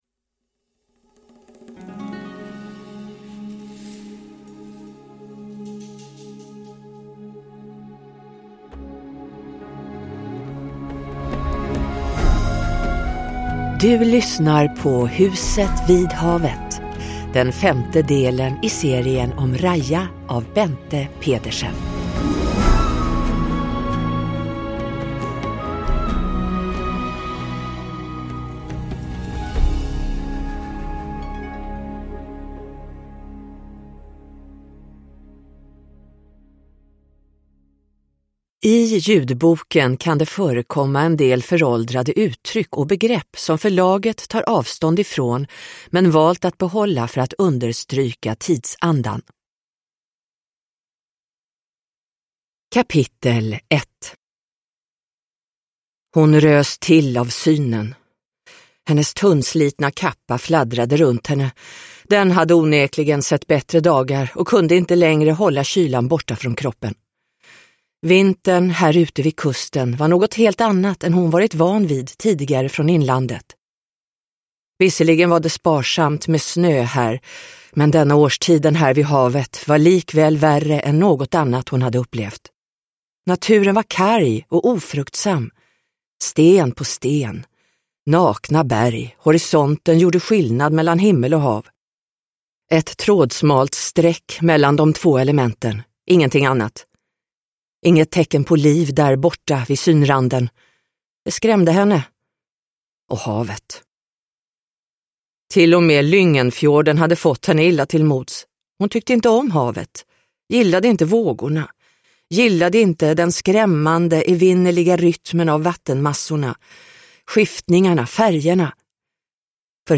Huset vid havet – Ljudbok – Laddas ner